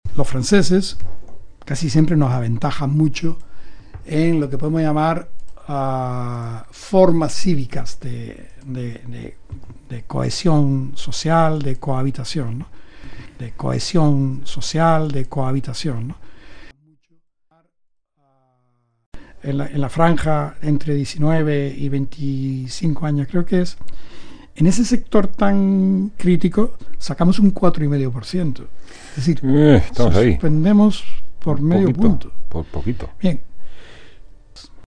Hace algunas semanas, fue entrevistado en RNE.